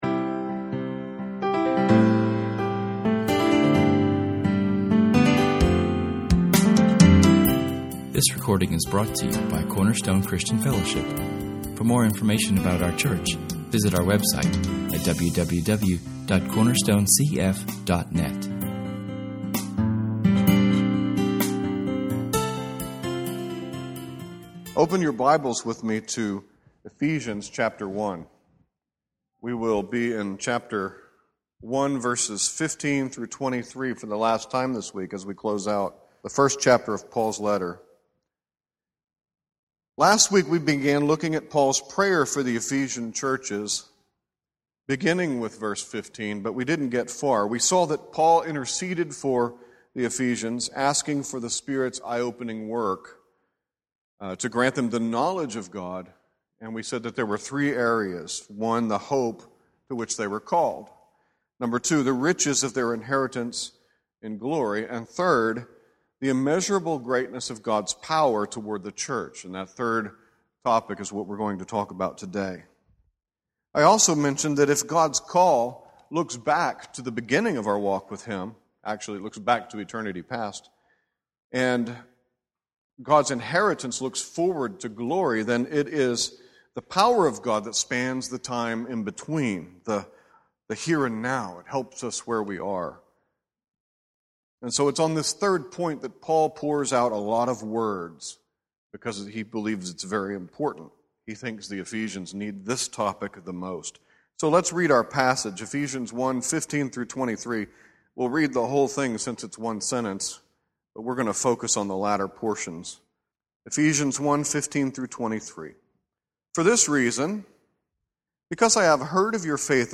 We learn three aspects of God’s mighty power – His resurrection power, his coronation power and his delegated power to the church. In this sermon we spend two thirds of our time on application and illustration, ending in the story of Horatio Spafford and the writing of “It Is Well With My Soul.”